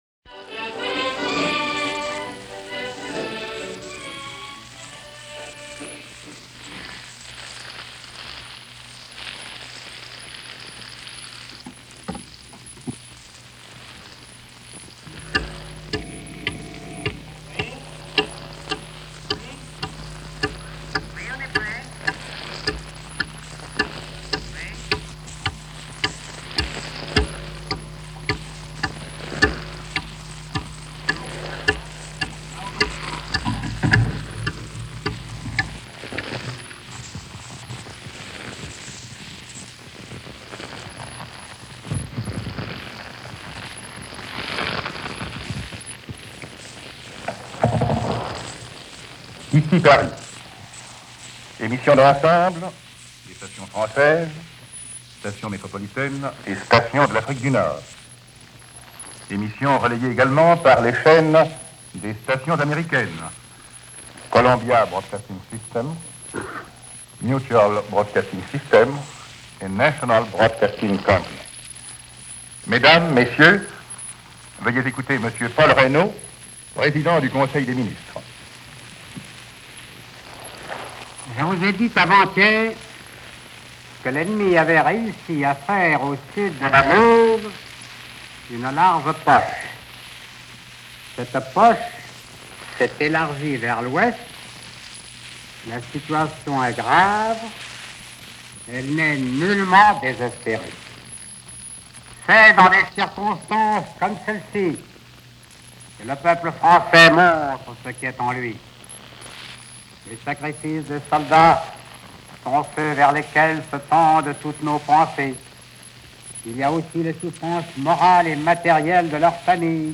Paul Reynaud: "The Situation Is Grave" - an Address To The World - June 16, 1940 - Paris Radio and relayed to CBS, MBS and NBC in America.
Premier Paul Reynaud – address to the world – Radio Paris, via Shortwave – June 16, 1940